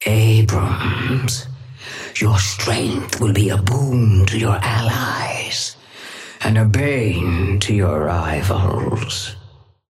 Sapphire Flame voice line - Abrams.
Patron_female_ally_atlas_start_03.mp3